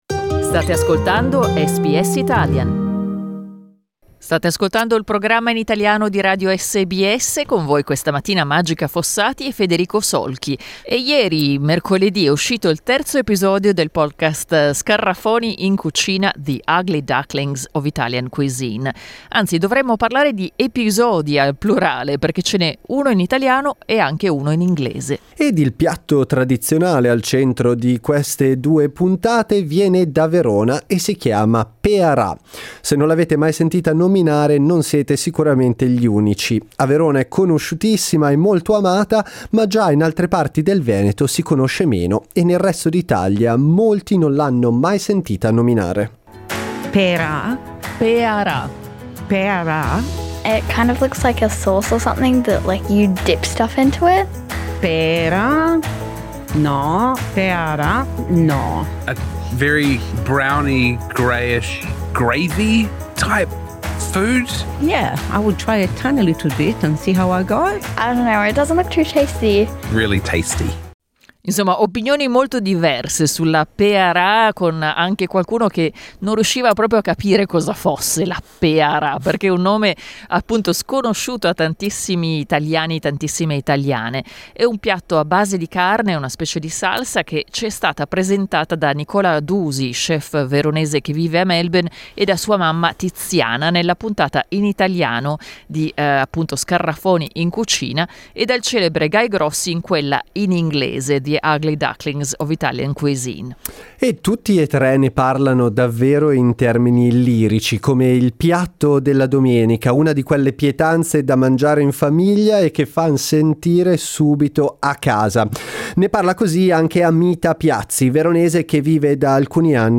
Voi avete un piatto tipico della vostra regione, della vostra città o paese, che associate alle domeniche o alle feste in famiglia? Lo abbiamo chiesto ad ascoltatori e ascoltatrici e ad alcuni ospiti.